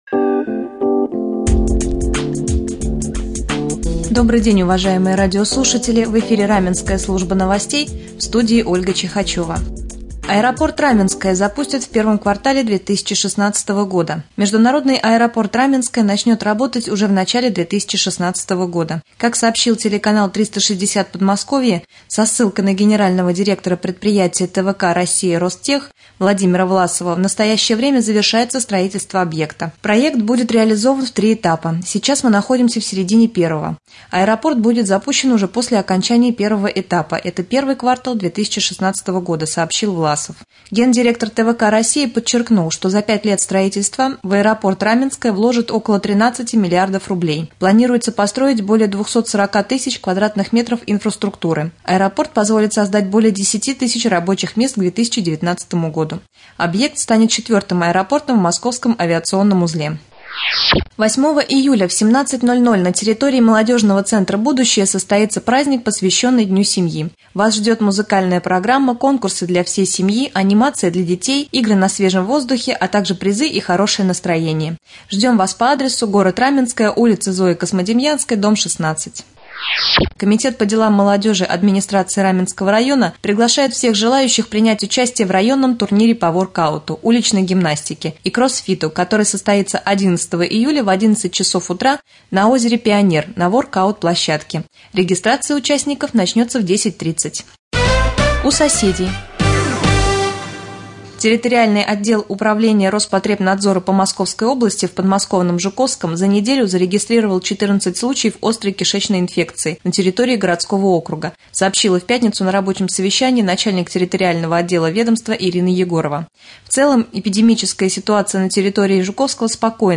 3. Новостной блок